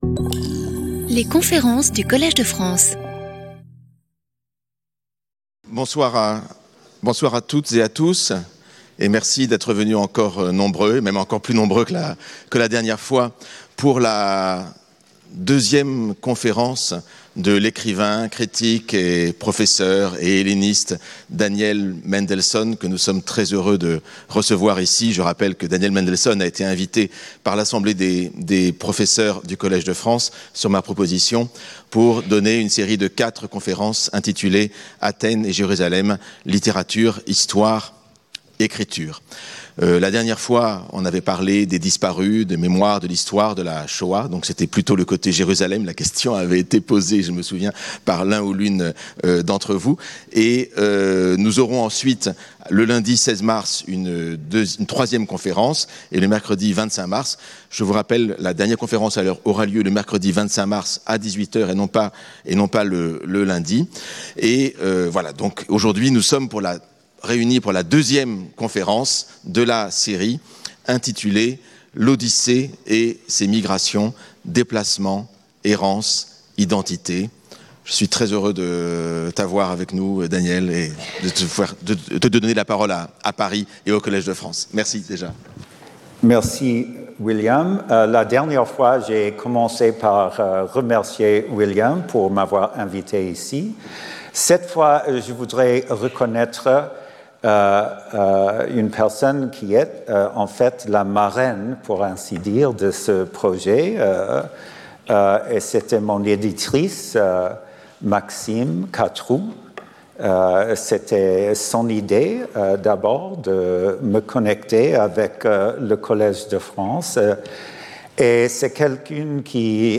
Dans cette conférence, Daniel Mendelsohn examine les voyages anciens de l’Odyssée et la manière dont ils peuvent éclairer les questions urgentes d’aujourd’hui : la migration, les réfugiés, la citoyenneté, et ce que signifie cartographier son identité, à la fois personnelle et politique, dans un monde aux frontières mouvantes.